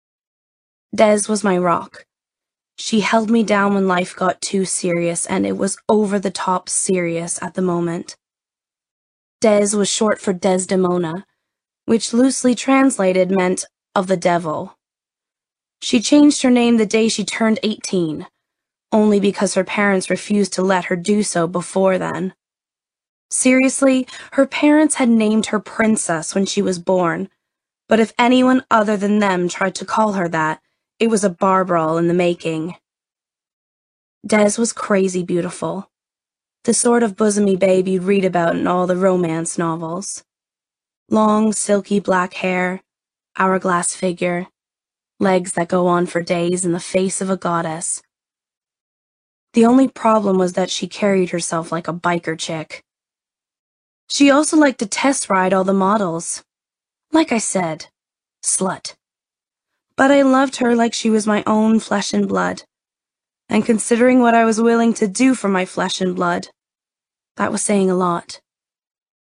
20/30's, North American, Versatile/Confident/Assured